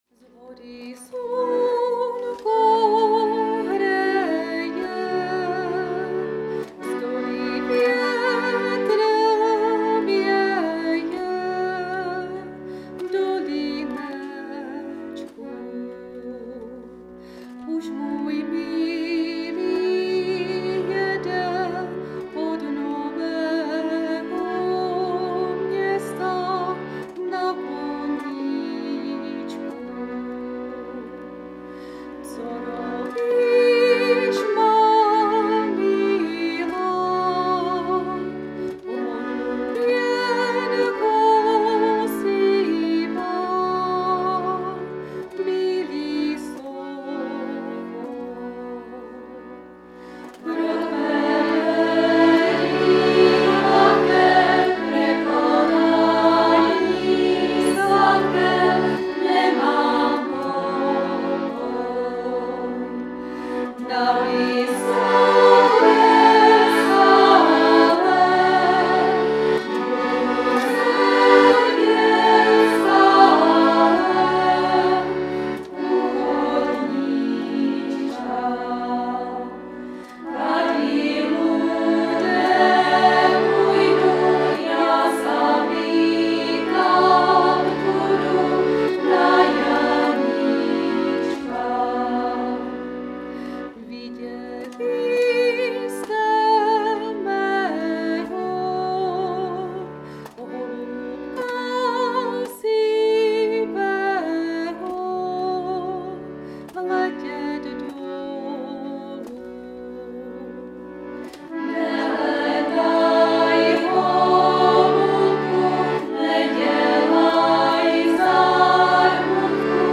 ❥ Ženský sboreček Nivnička ♡
cimbál